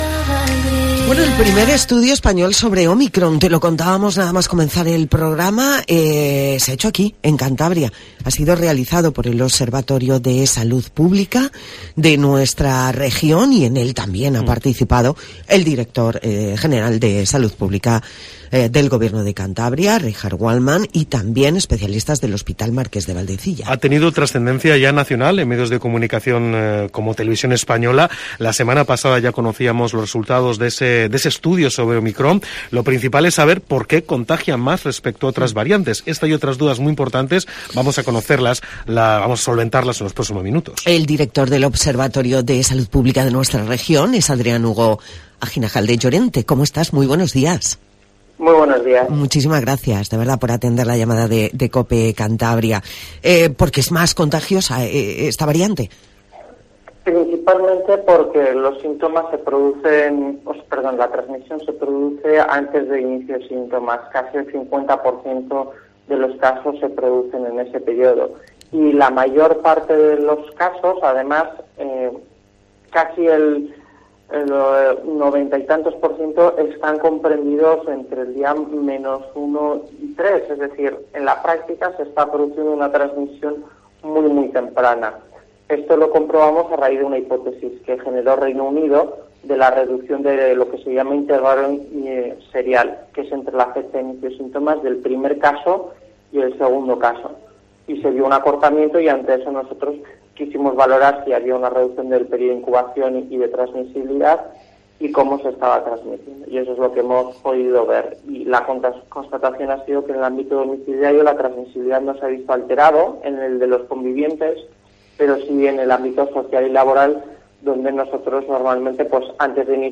ctv-xph-entrevista-omicron